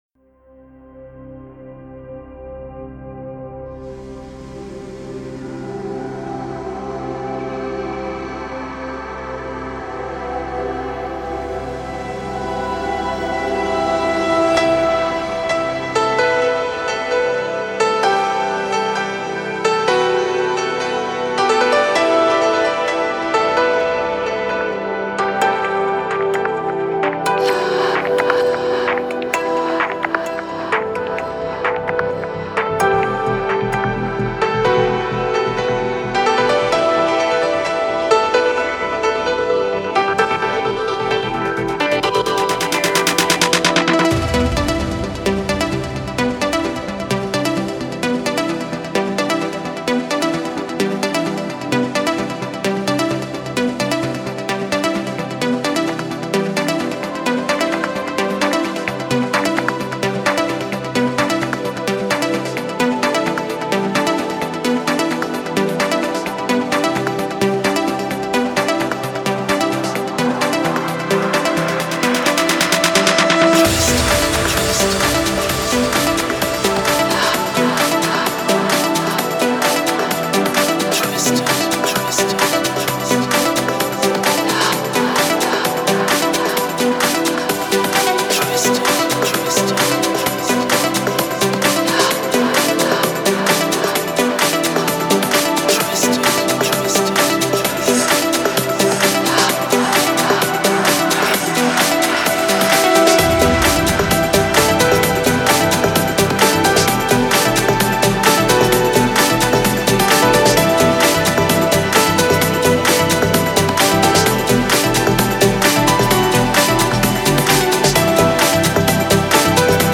Жанр: Trance | Progressive